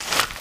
MISC Newspaper, Scrape 05.wav